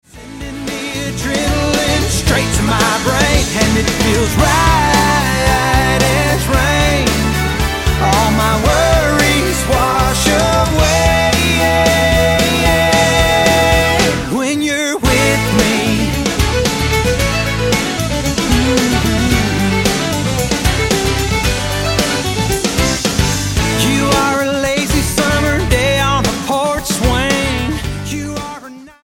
STYLE: Country